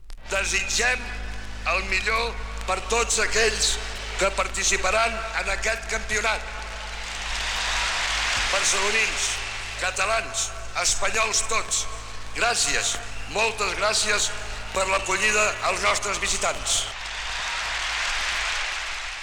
Transmissió de la cerimònia inaugural del Mundial de Futbol masculí 1982, des del Camp Nou de Barcelona.
Esportiu